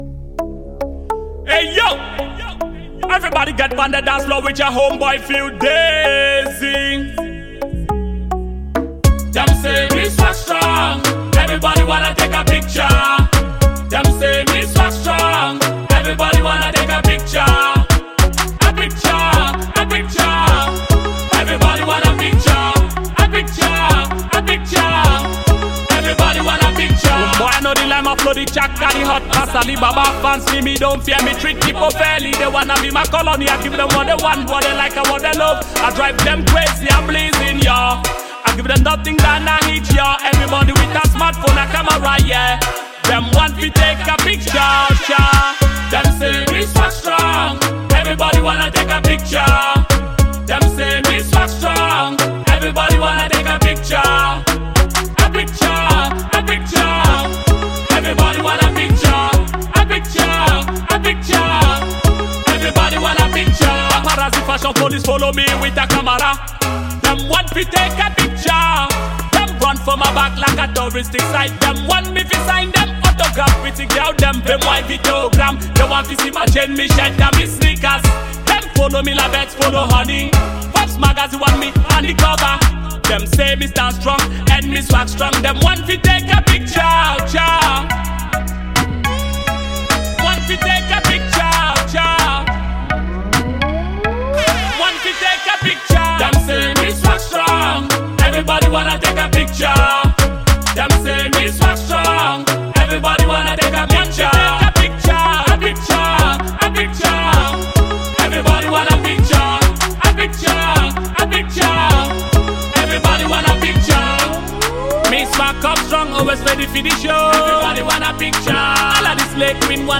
fusion of hip-hop rock